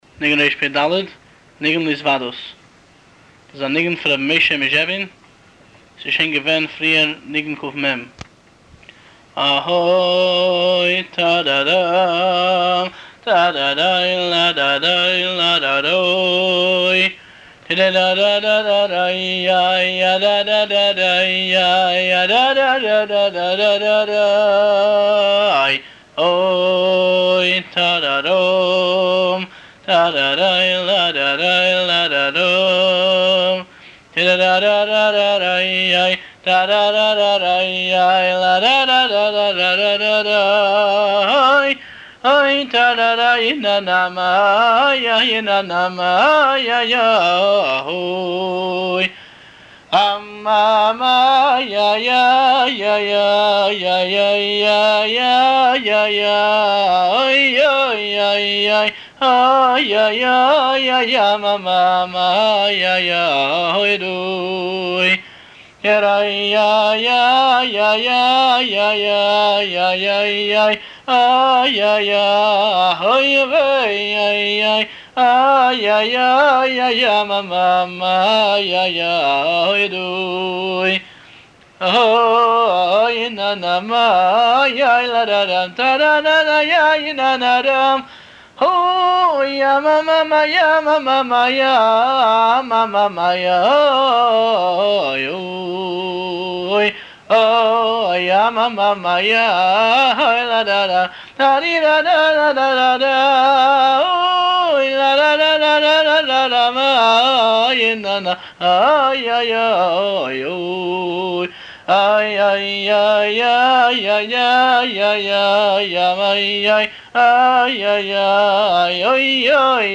הניגון